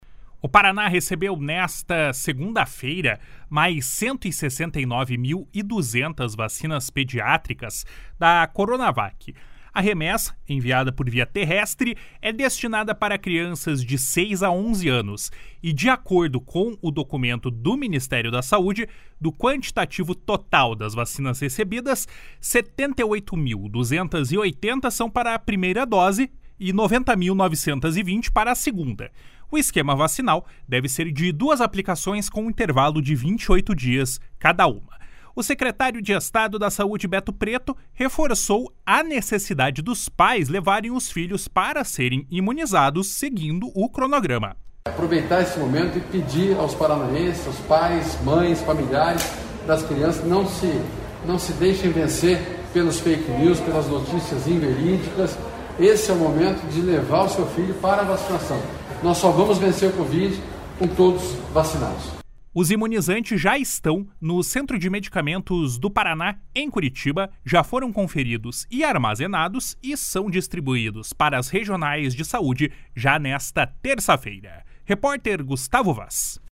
O secretário de Estado da Saúde, Beto Preto, reforçou a necessidade dos pais levarem os filhos para serem imunizados seguindo o cronograma. // SONORA BETO PRETO //